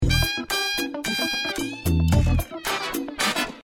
Electro Swing, Jazzy House, Funky Beats DJ for Hire